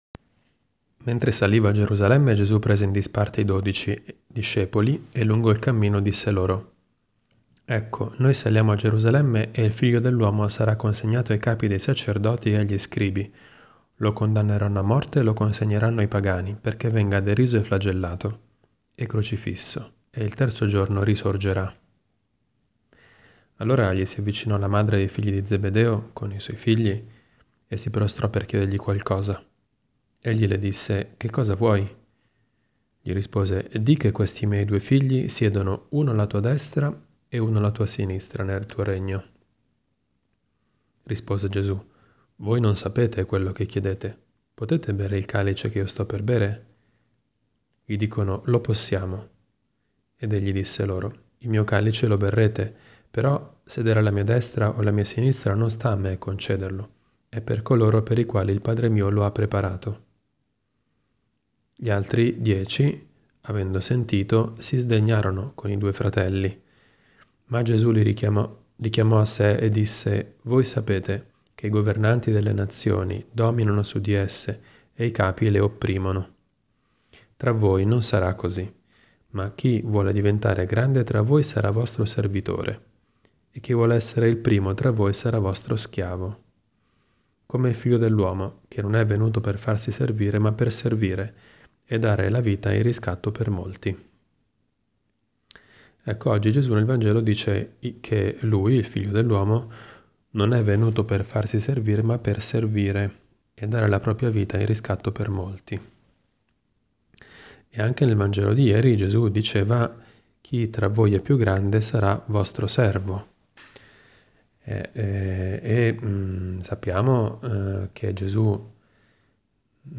Commento al vangelo (Mt 5,17-19) del 28 febbraio 2018, mercoledì della II settimana di Quaresima.